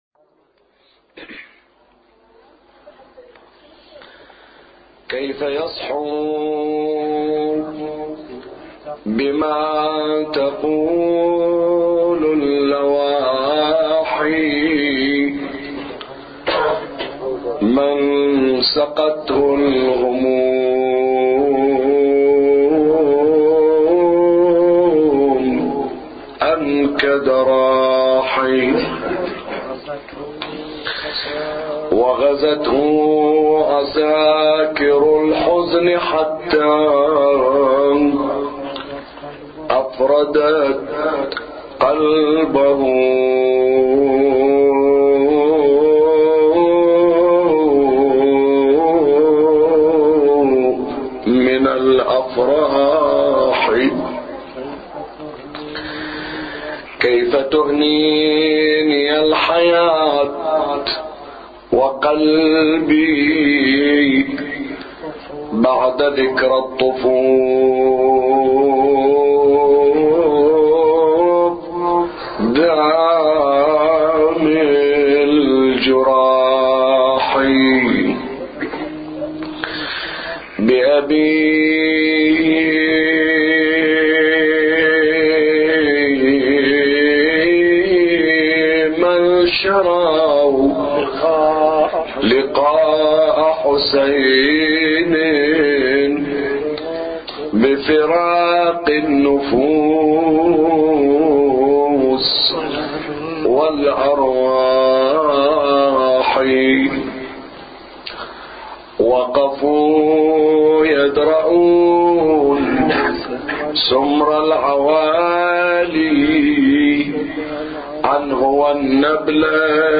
أبيات حسينية – ليلة السادس من شهر محرم